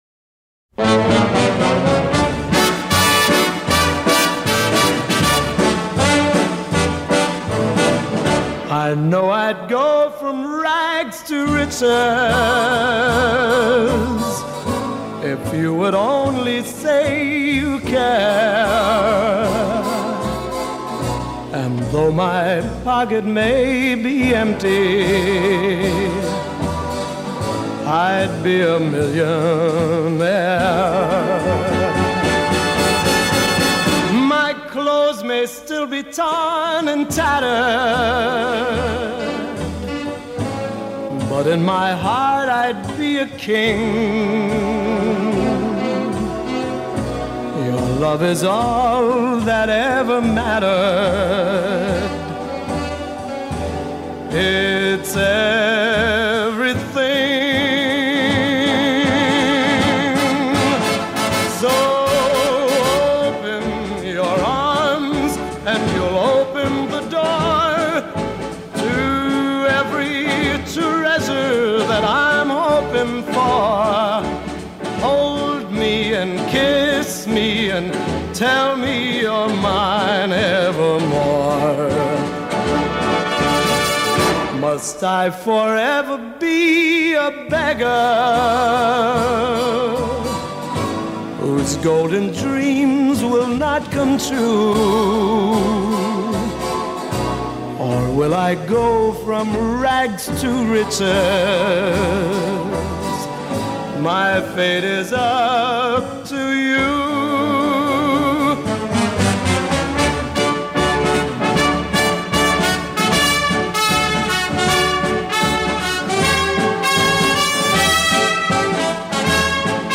Soundtrack, Rock, Soul